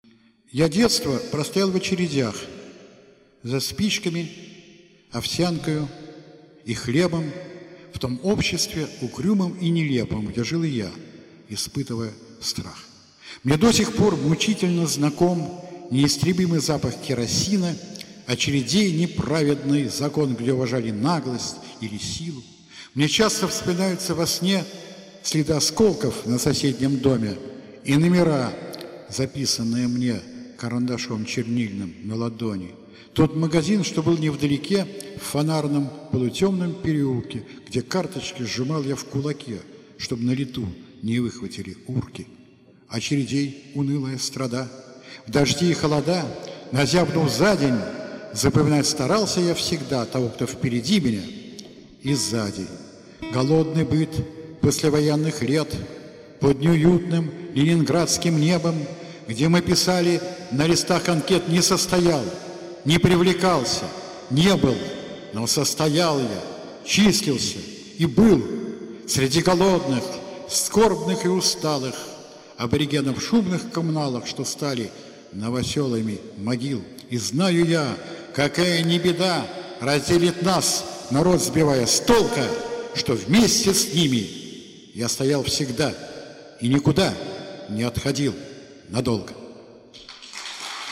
ochered-gorodnitskiĭ---(ya-detstvo-prostoyal-v-ocheredyah-)(stihotvorenie).mp3